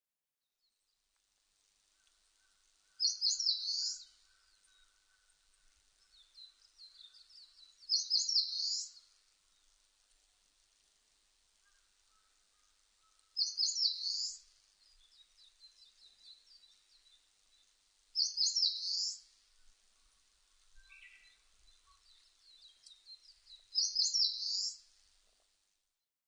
センダイムシクイ　Phylloscopus coronatusウグイス科
日光市稲荷川中流　alt=730m  HiFi --------------
MPEG Audio Layer3 FILE  Rec.: SONY MZ-NH1
Mic.: Sound Professionals SP-TFB-2  Binaural Souce
他の自然音：　 メジロ・ハシブトガラス・ウグイス